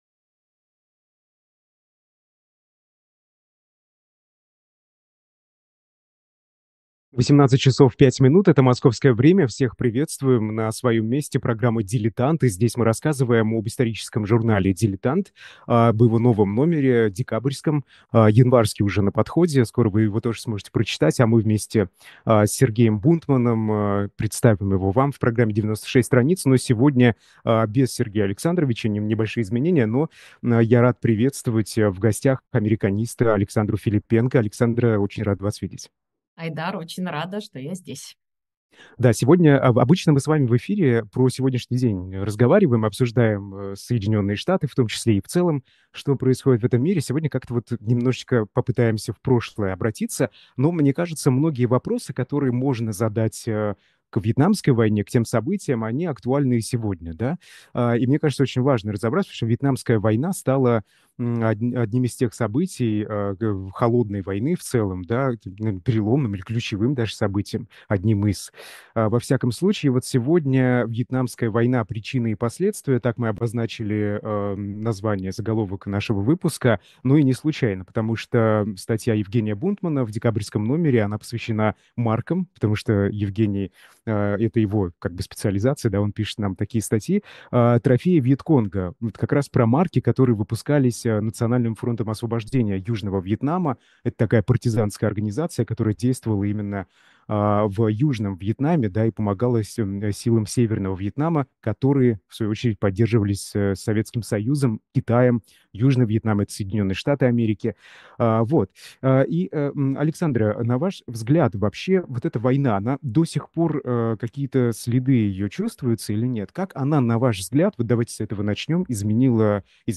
В эфире американист